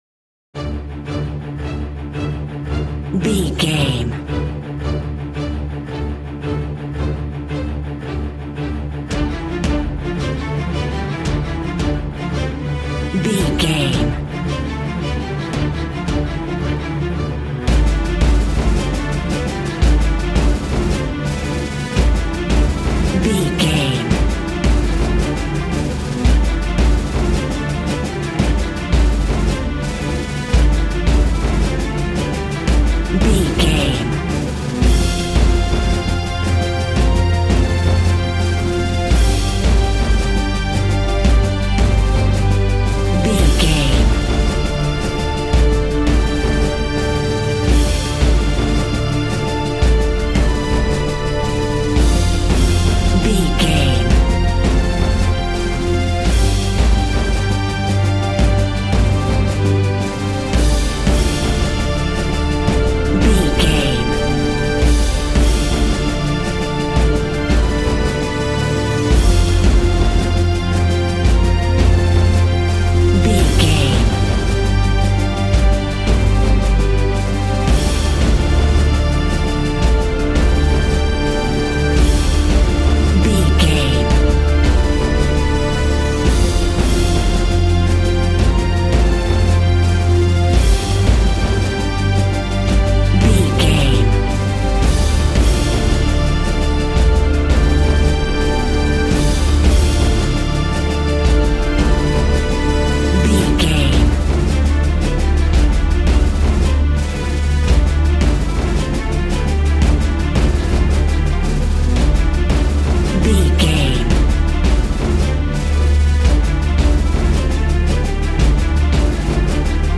Epic / Action
Fast paced
In-crescendo
Uplifting
Aeolian/Minor
strings
brass
percussion
synthesiser